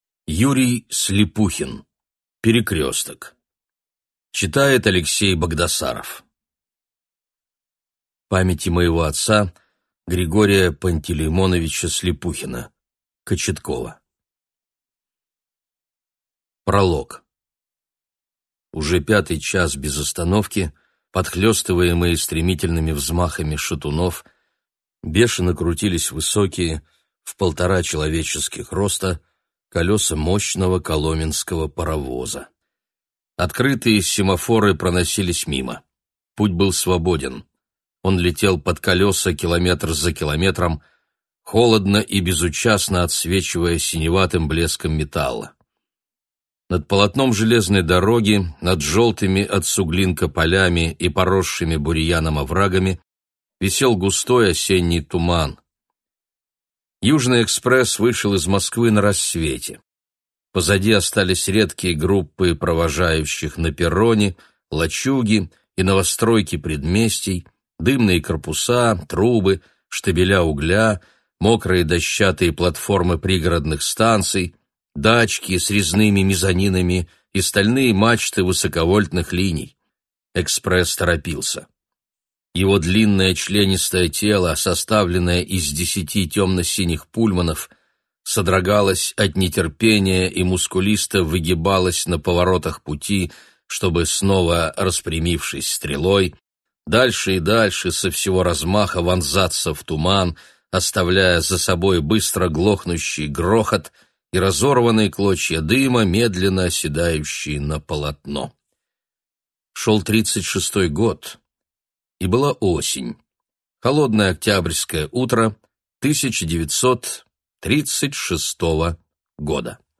Аудиокнига Перекресток | Библиотека аудиокниг